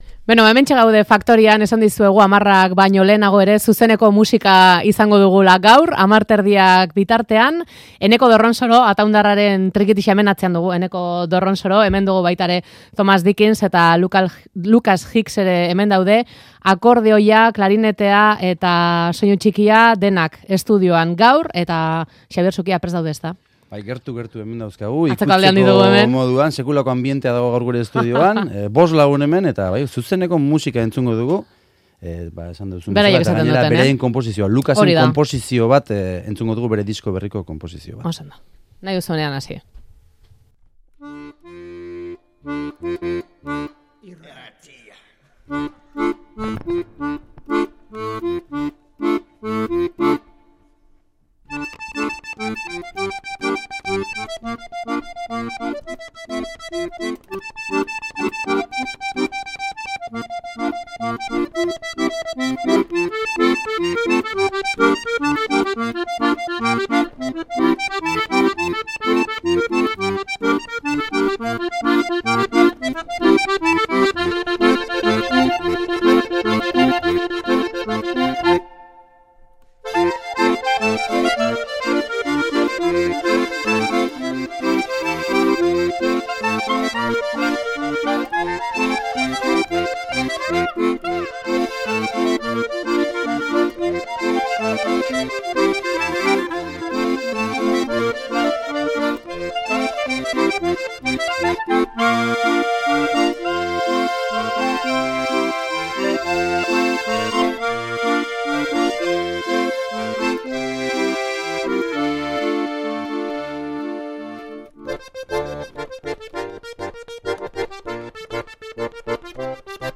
'Banjoa eta trikitixak oso ondo egiten dute bat''
klarinetea
banjoaren arteko fusioa zuzenean.